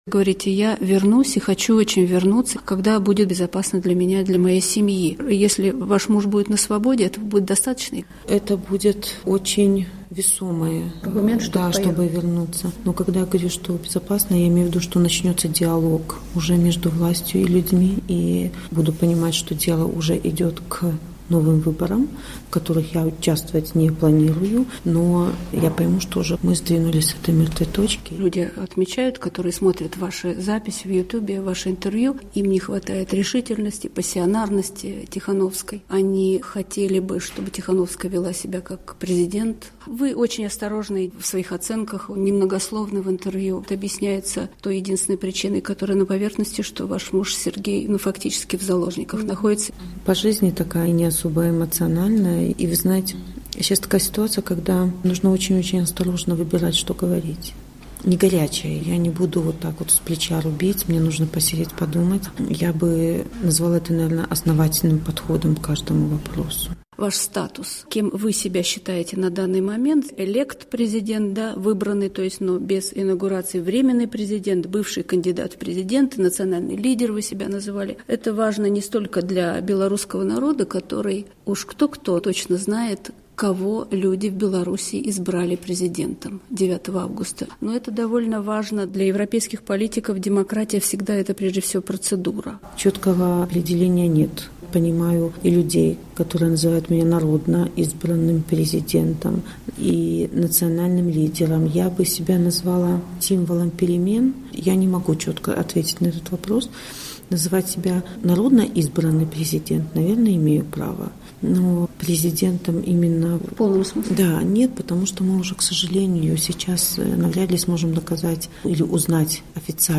Интервью со Светланой Тихановской